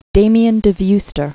(dāmē-ən də vystər, dä-myăɴ də vœ-stĕr), Joseph Known as "Father Damien." 1840-1889.